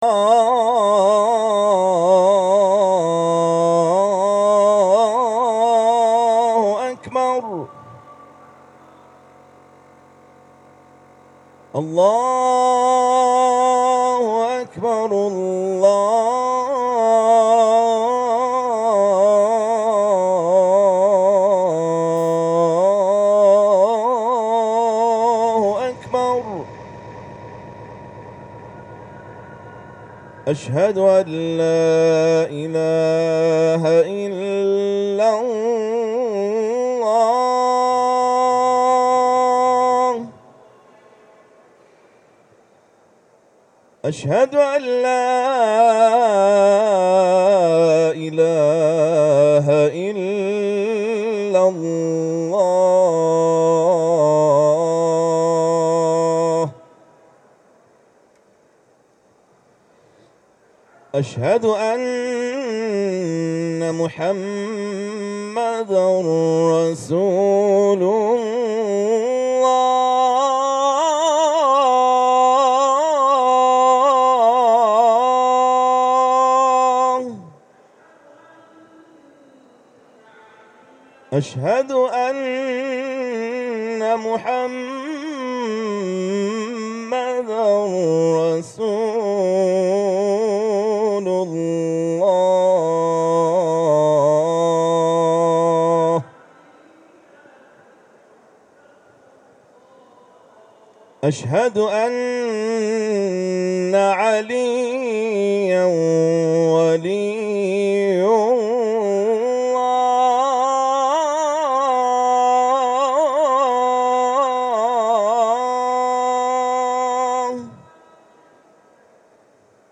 طنین اذان قاری برجسته در نمایشگاه مطبوعات
گروه فعالیت‌های قرآنی: یکی از قاریان کشورمان با حضور در غرفه ایکنا در نمایشگاه مطبوعات علاوه بر تلاوت آیاتی از کلام الله مجید به اجرای زنده اذان پرداخت.
به گزارش خبرگزاری بین المللی قرآن (ایکنا)، اذان مغرب اولین روز نمایشگاه مطبوعات و خبرگزاری‌ها با صدای قاری ممتاز کشورمان و اجرا در غرفه ایکنا در فضای مصلای تهران طنین‌انداز شد.
اذان